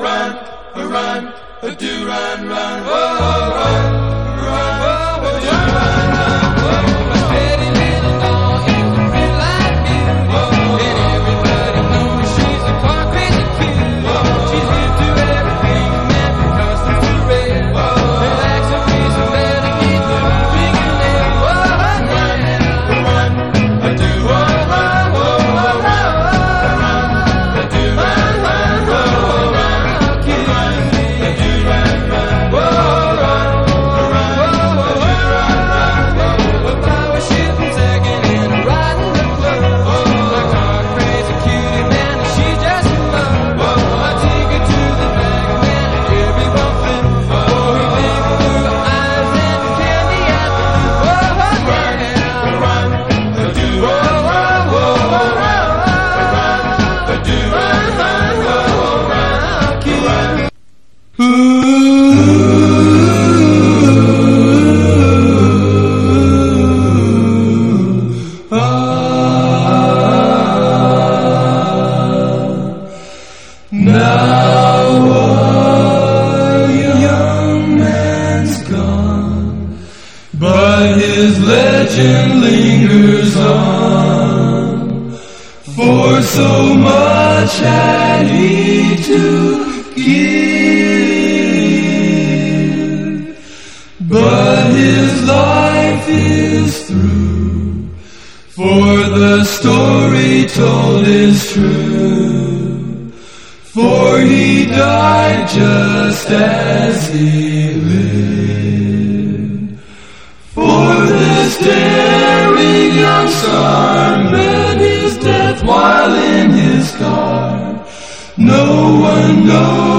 ROCK / 60'S / JAZZ ROCK / PSYCHEDELIC / PROGRESSIVE ROCK
ジャズ・ロック色強めのファースト・アルバム！